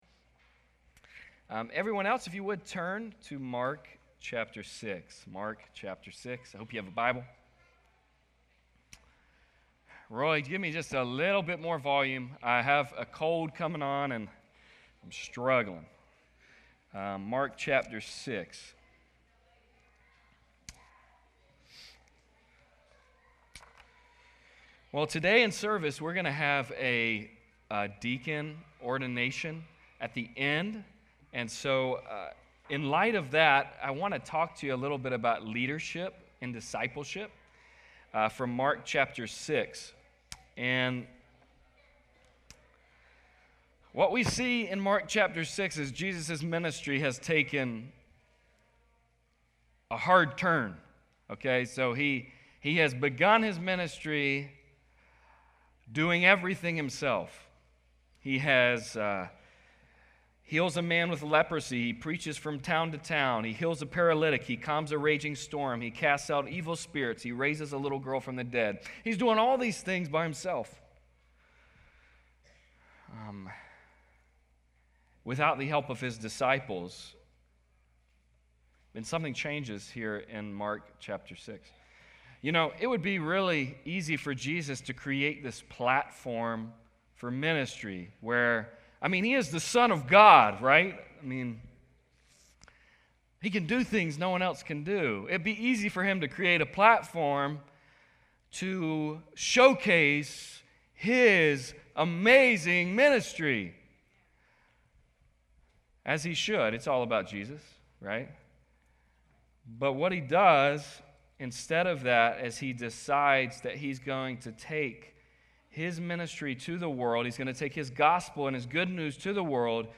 Mark 6:7-13 Service Type: Sunday Service « Redemptive Relationships Where is God Taking Us?